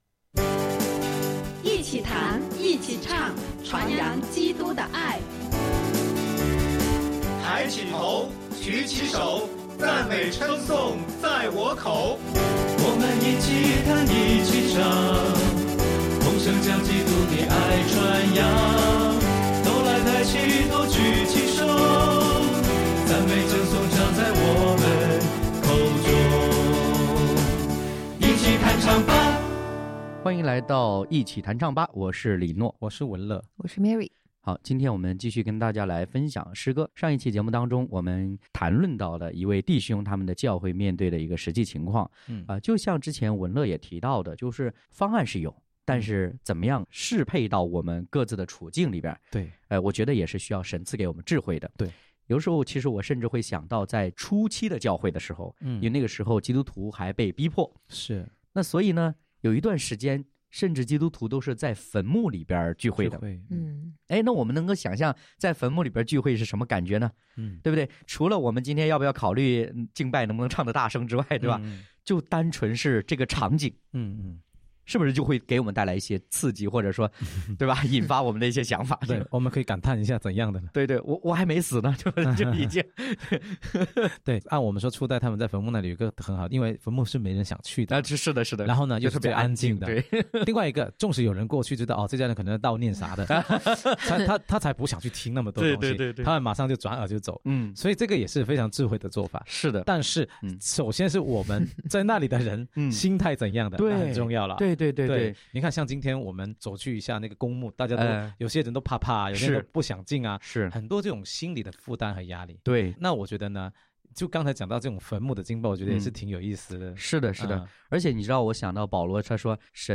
一起弹唱吧！